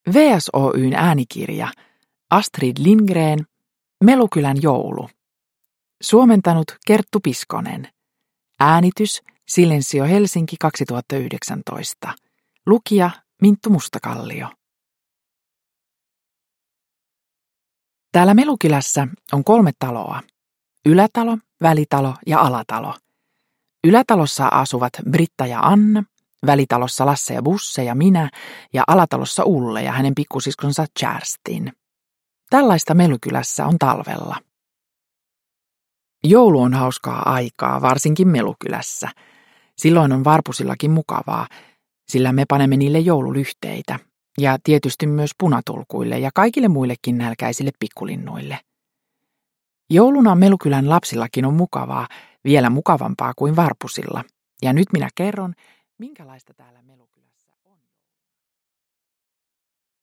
Melukylän joulu – Ljudbok – Laddas ner
Uppläsare: Minttu Mustakallio